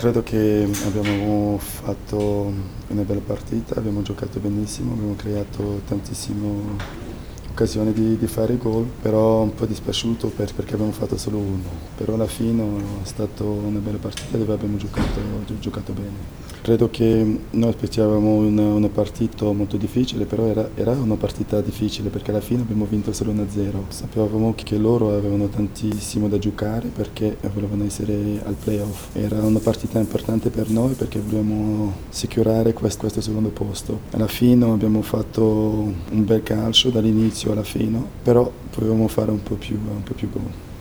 Le interviste post-partita:
patrick-vieira-nyc-fc.mp3